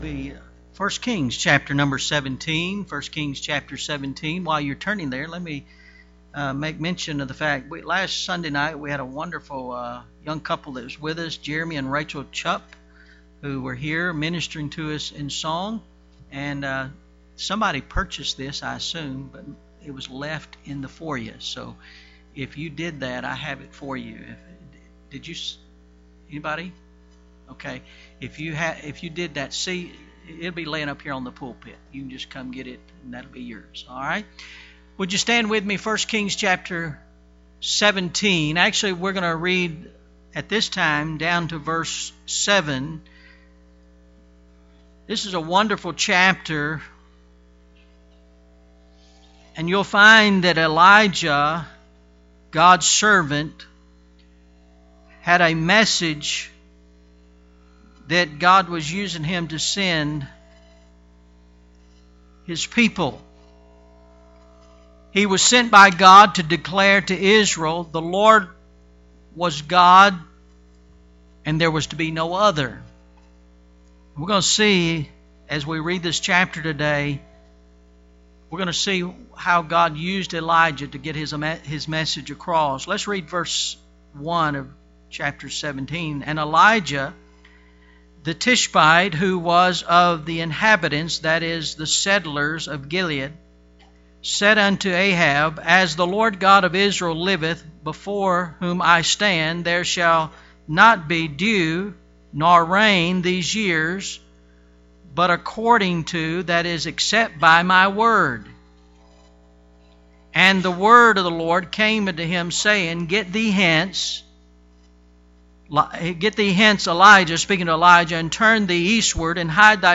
Can God Really Meet My (Your) Need? – November 30th, 2014 – AM Service